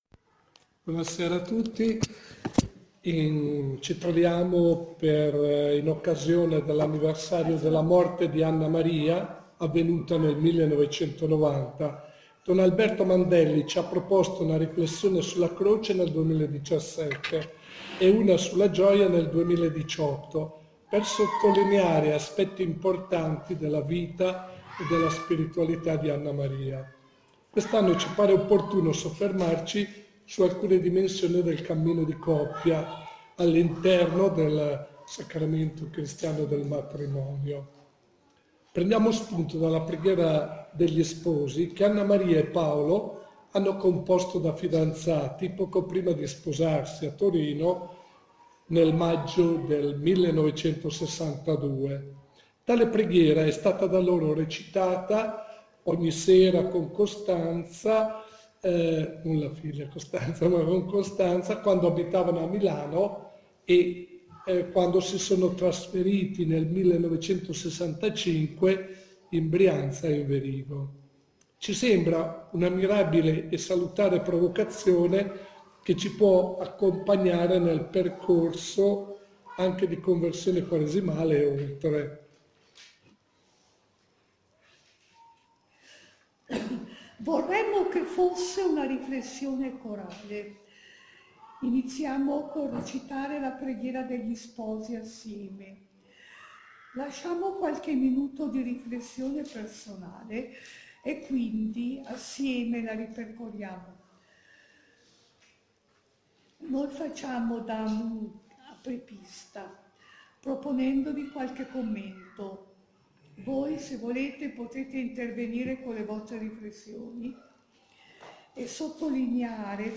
Villa Romanò d’Inverigo, 13 aprile 2019
LA REGISTRAZIONE AUDIO DELL’INCONTRO